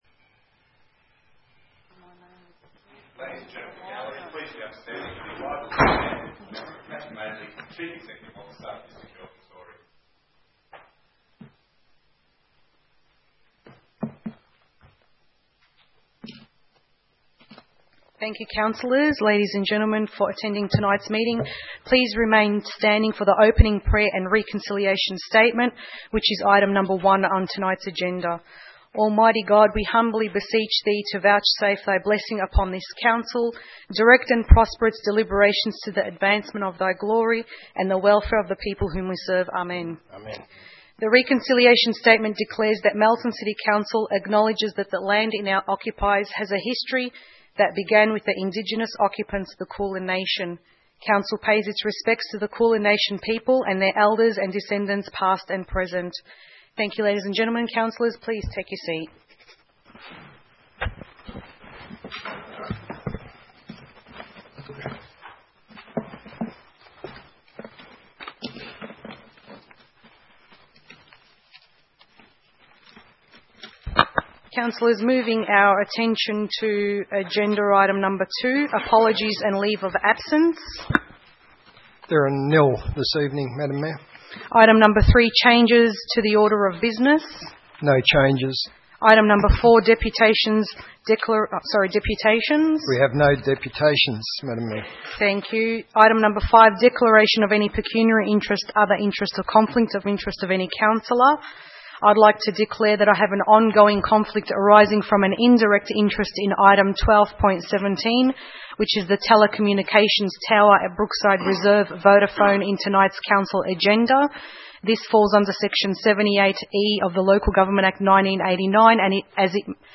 30 May 2016 - Ordinary Council Meeting